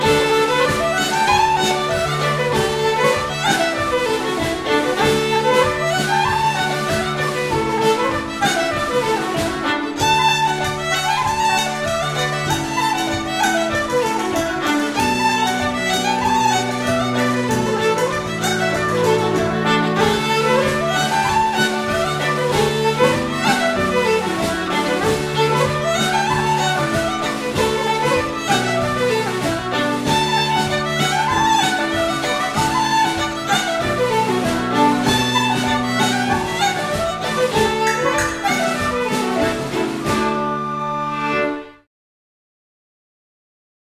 Irish traditional music